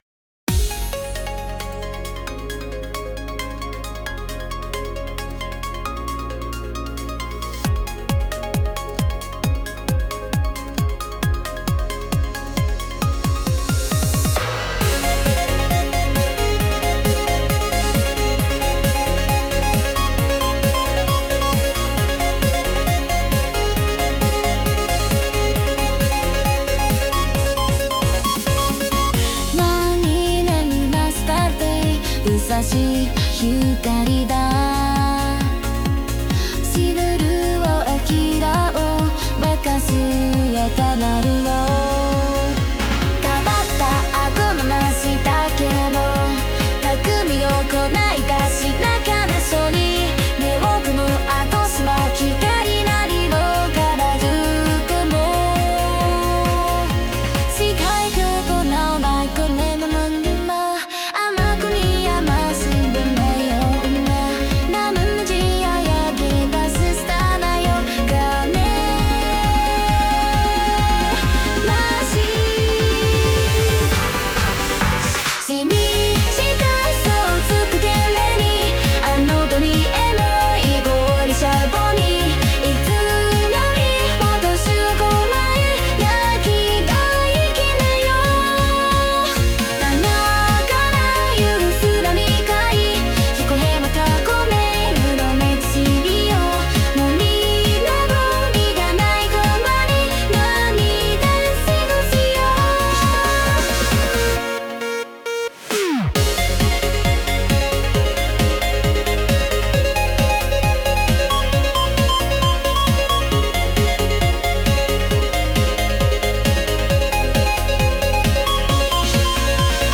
• ジャンル：エラーポップアイドル
• 声：透明感のあるヒロインボイス